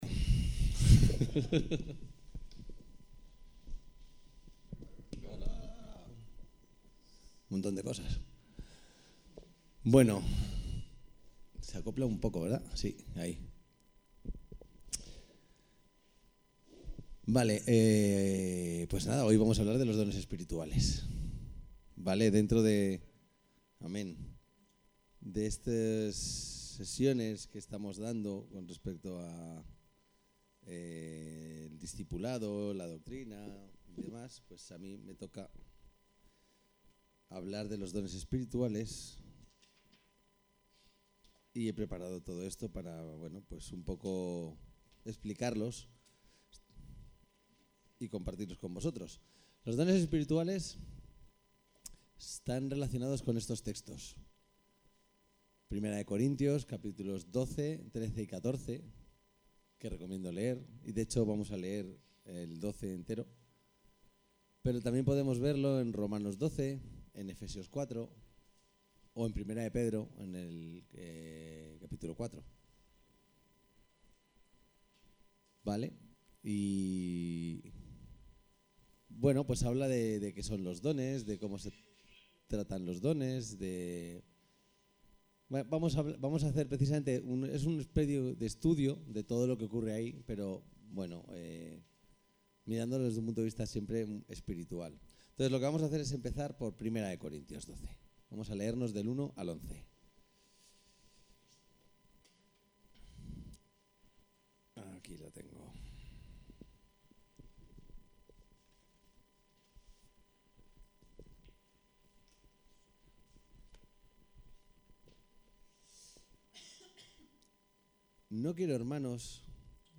Predicaciones